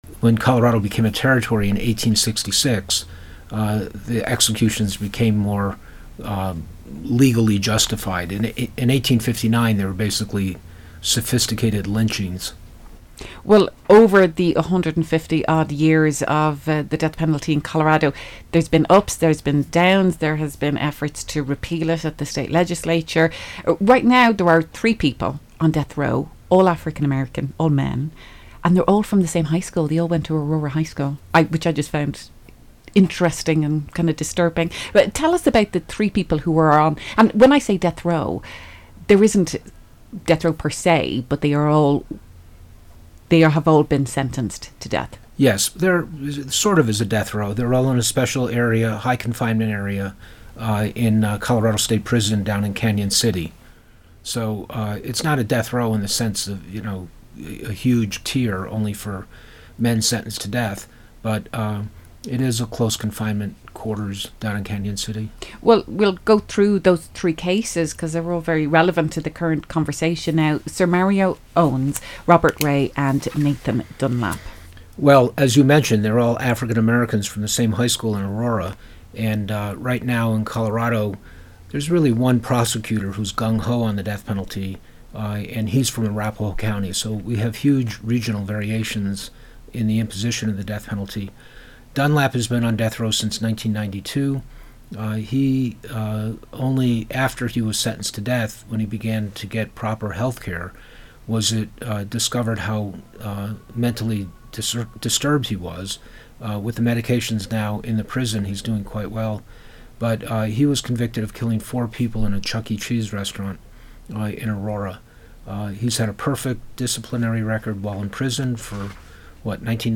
Sociology professor